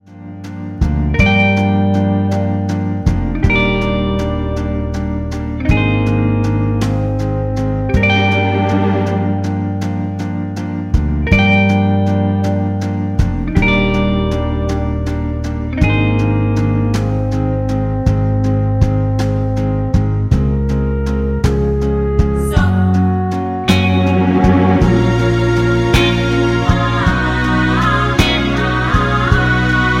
Backing track files: Oldies (1113)